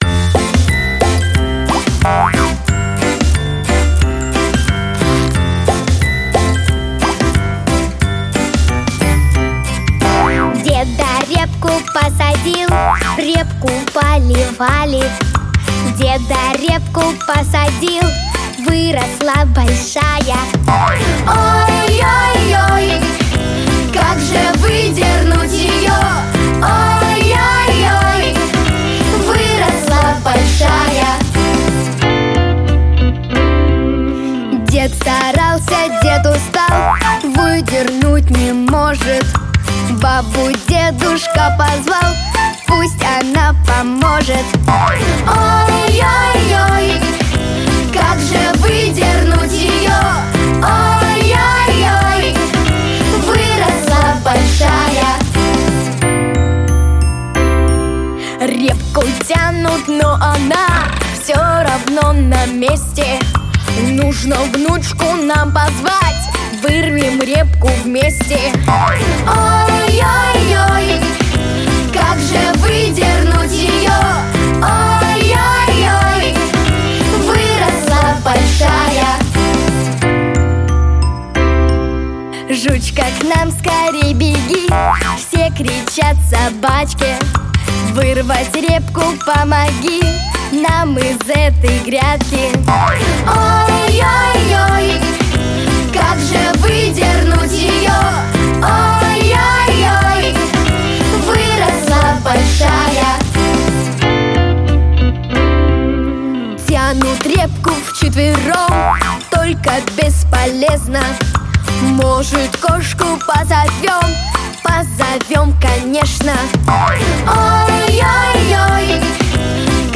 детские песни и музыку
Песни из мультфильмов